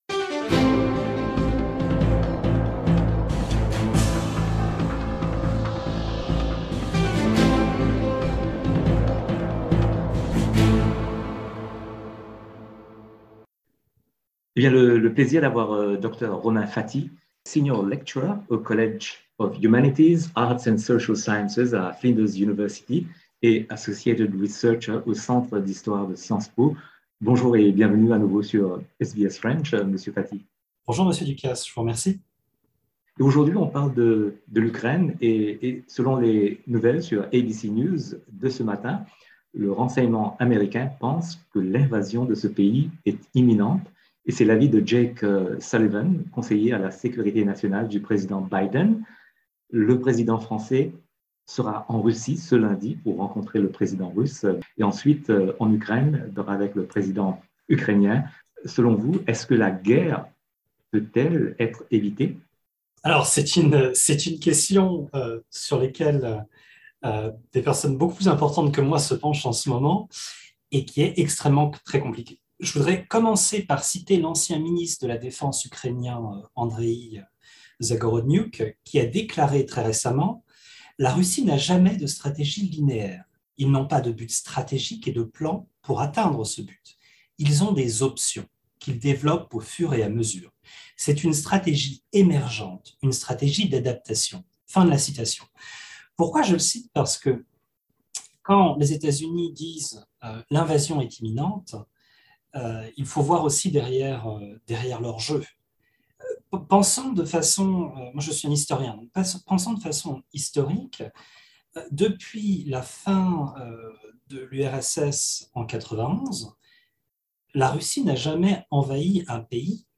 Source: Zoom SBS en français